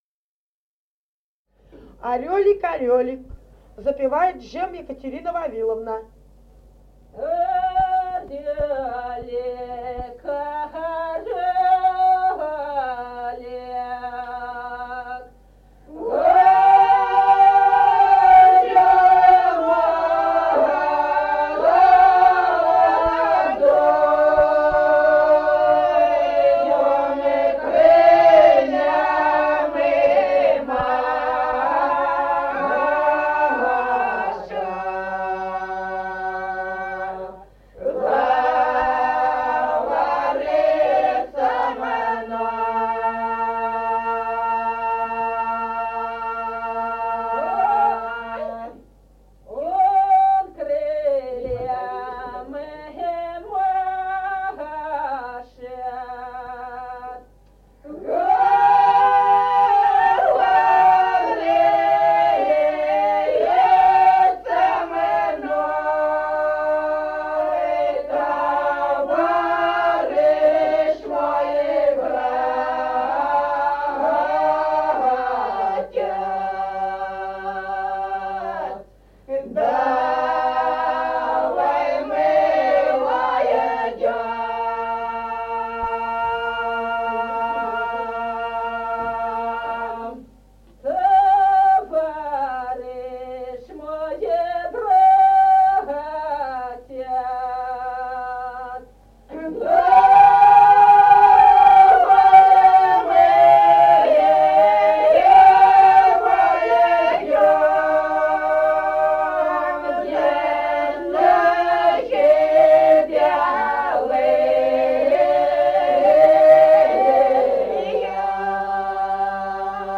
Народные песни Стародубского района «Орёлик», лирическая.
с. Остроглядово.